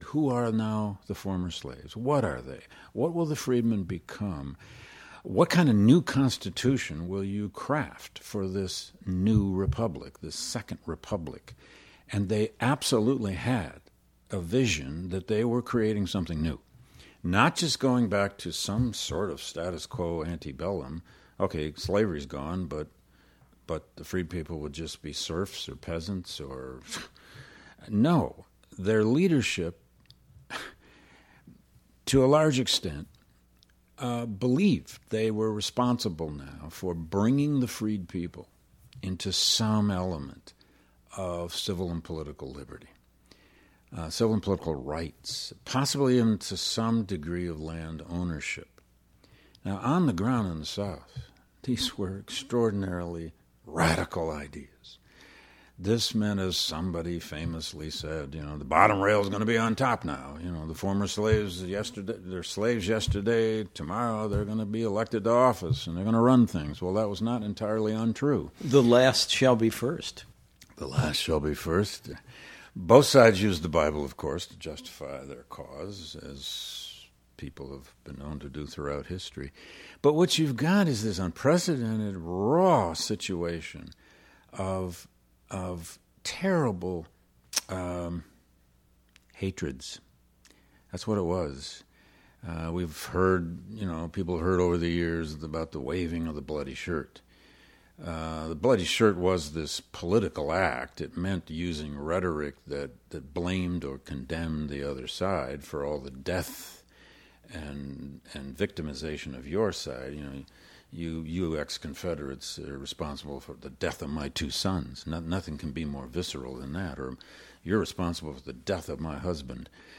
Web Extra(additional audio from our interviews)
Yale historian David Blight discusses the social condition of the United States following emancipation and addresses